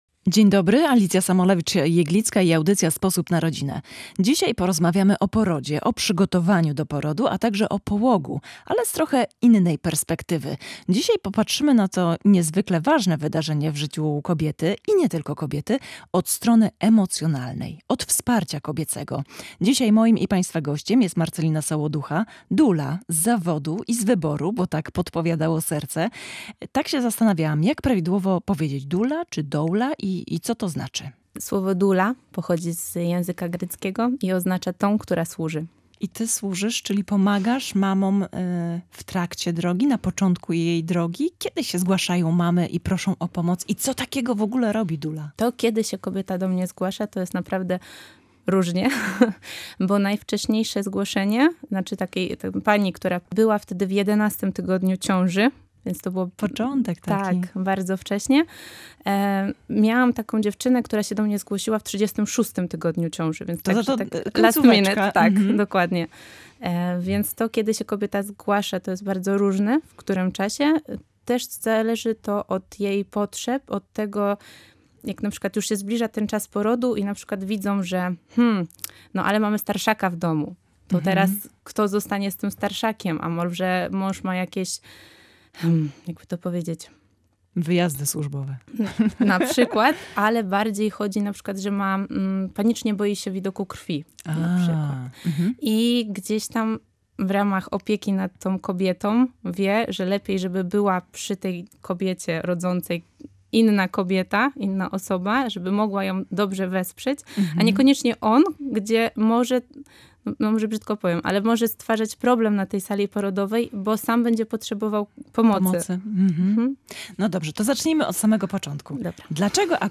W audycji „Sposób na rodzinę” rozmawialiśmy o porodzie, przygotowaniu do porodu, a także o połogu... ale z trochę innej perspektywy.